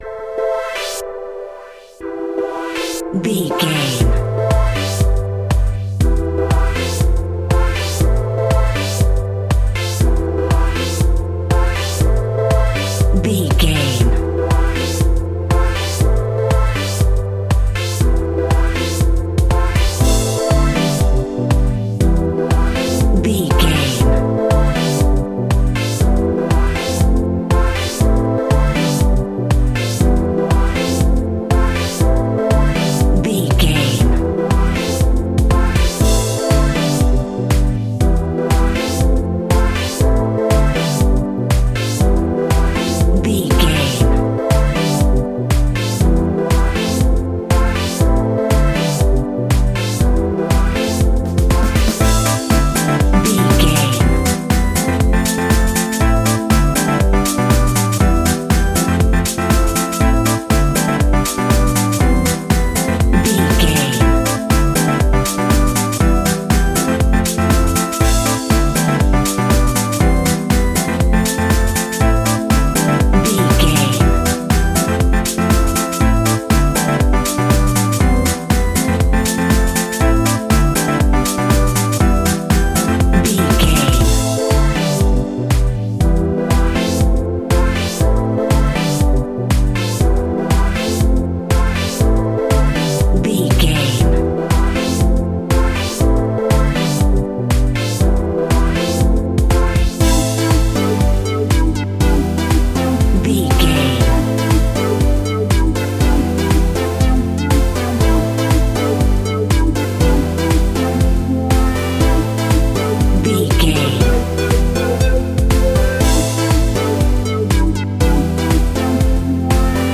Electro Funk House.
Aeolian/Minor
groovy
uplifting
driving
energetic
drums
synthesiser
bass guitar
funky house
electronic
nu disco
upbeat
funky guitar
clavinet
synth bass